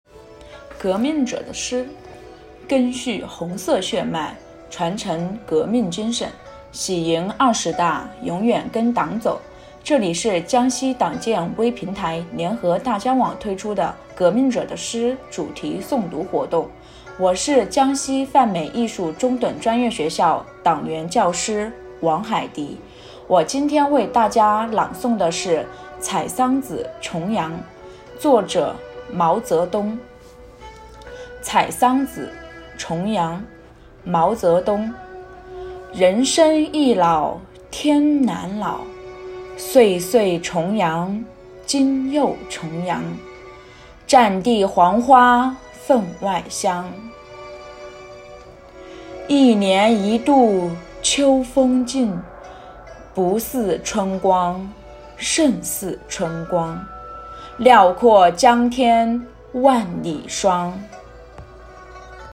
【革命者的诗主题诵读】第四期：毛泽东《采桑子·重阳》